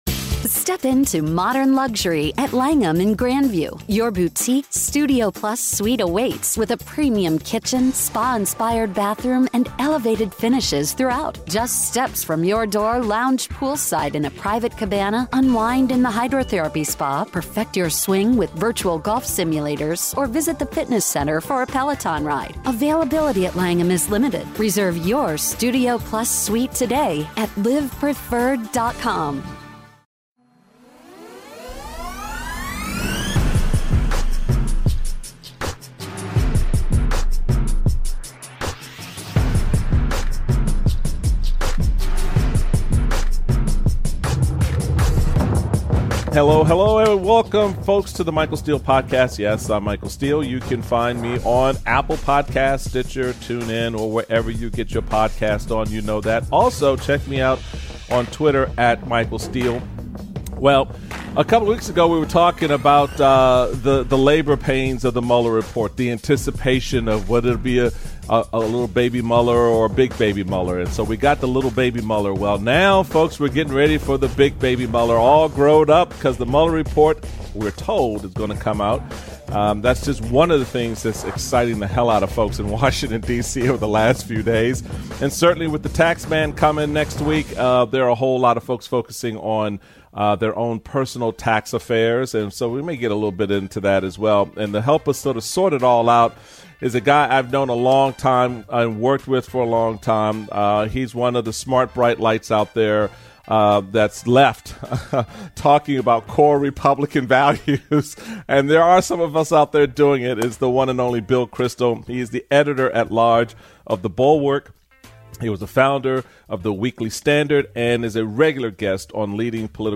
How Did The GOP Get It So Ass Backwards? With Guest Bill Kristol